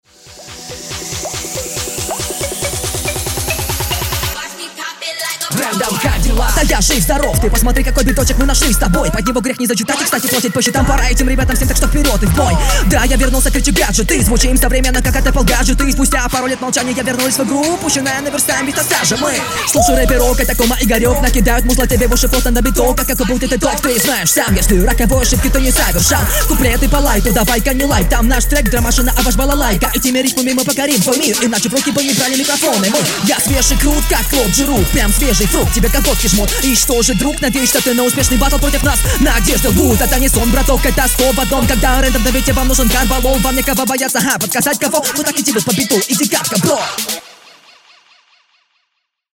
Нормуль флоу, раскрытие нечитабельное.
Стильная тараторка